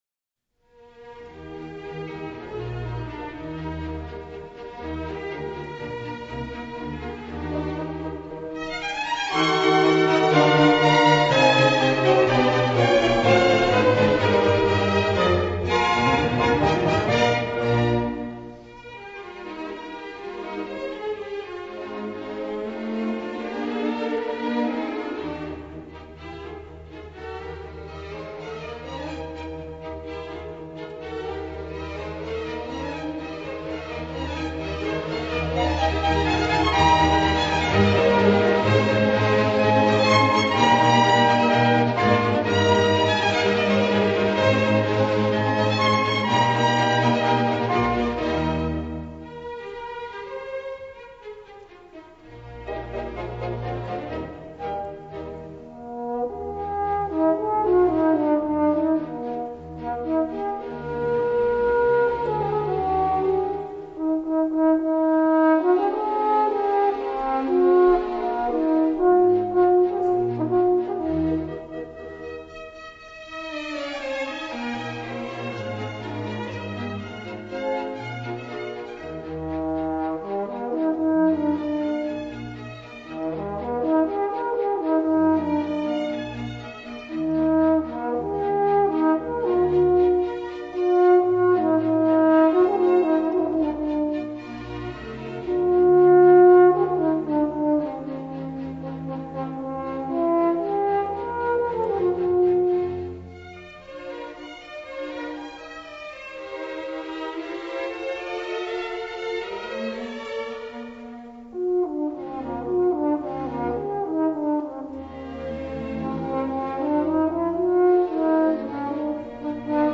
- 호른 협주곡 제3번 내림마장조 K.447
제1악장Allegro      제2악장 Ronmanza      제3악장 Allegro